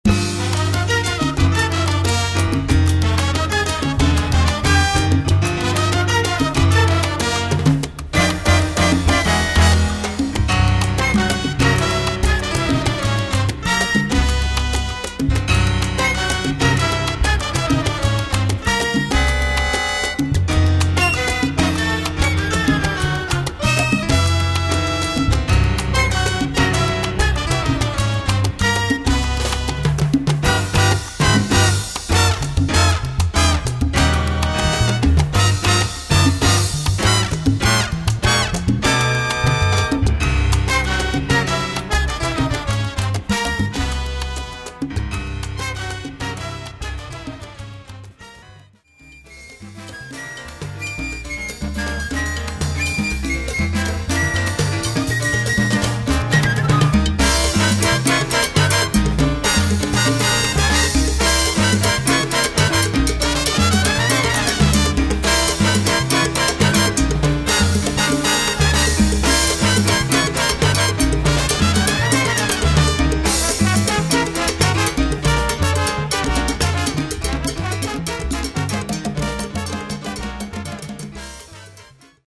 Category: combo
Style: cha cha
Solos: open
Instrumentation: flute, tenor, trumpet, trombone 1-2, rhythm